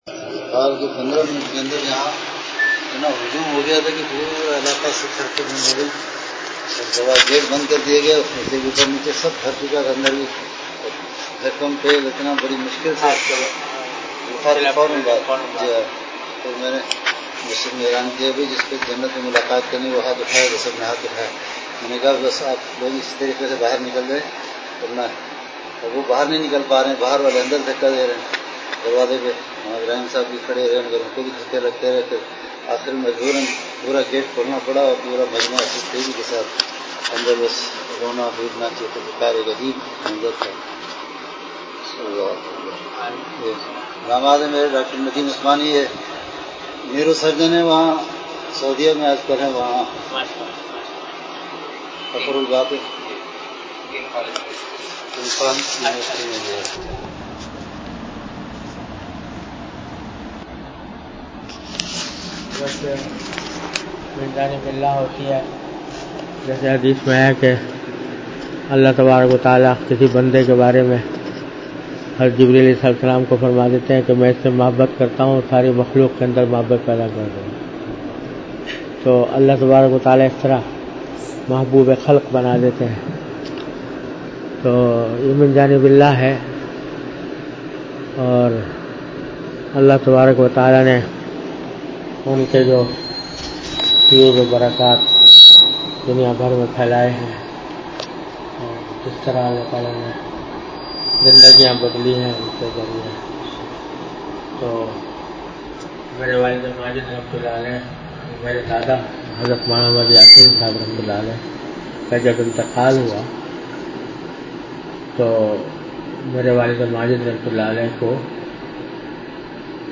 An Islamic audio bayan by Hazrat Mufti Muhammad Taqi Usmani Sahab (Db) on Bayanat. Delivered at Khanqah Imdadia Ashrafia.